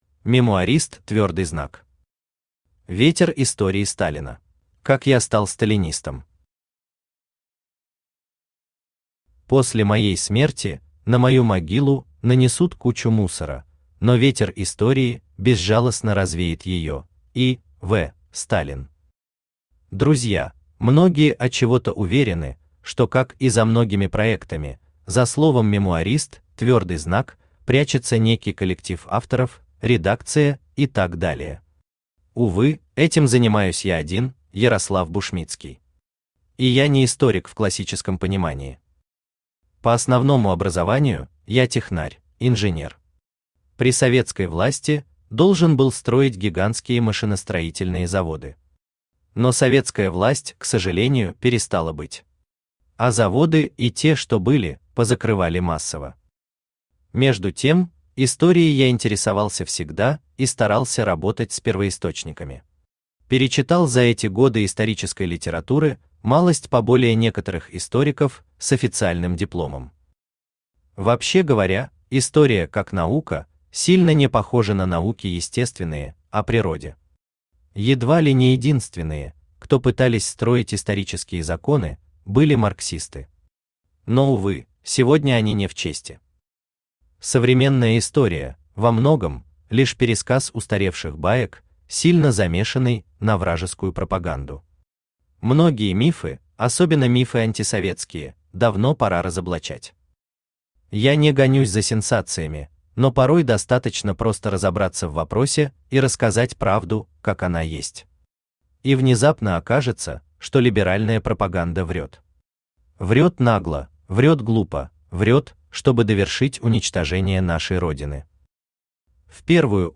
Аудиокнига Ветер истории Сталина | Библиотека аудиокниг
Aудиокнига Ветер истории Сталина Автор МемуаристЪ Читает аудиокнигу Авточтец ЛитРес.